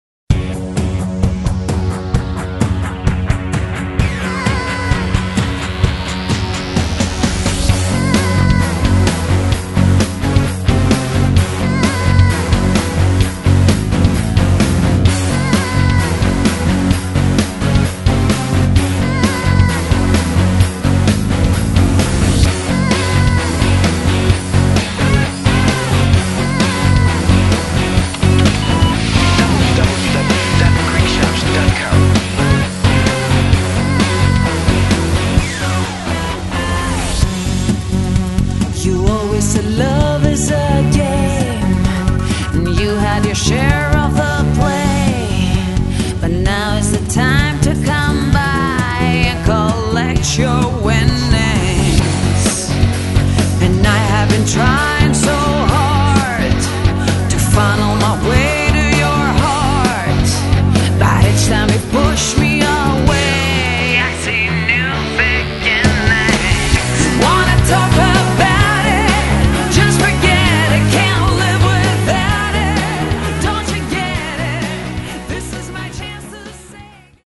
Greek Rock/Pop band